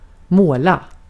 Uttal
Synonymer tjära färga Uttal Okänd accent: IPA: /ˈmoːˌla/ Ordet hittades på dessa språk: svenska Ingen översättning hittades i den valda målspråket.